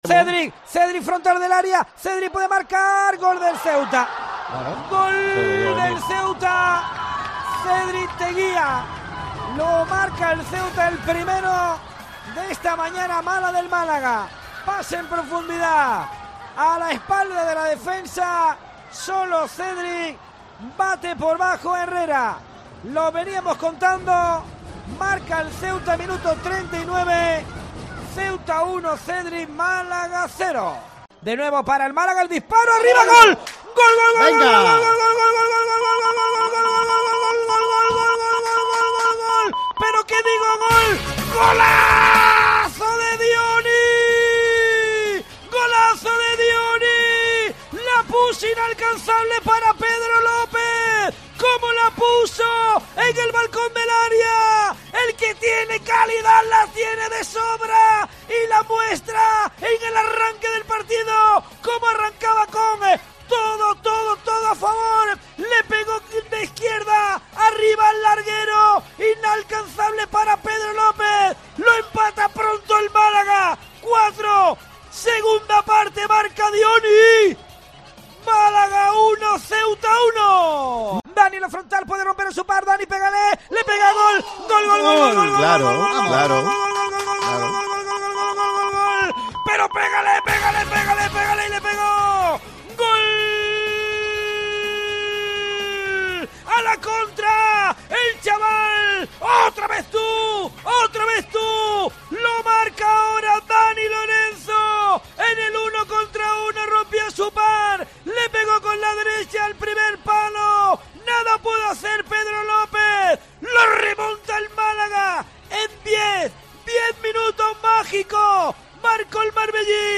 Así te hemos narrado los goles en la derrota del Málaga en Ceuta (3-2)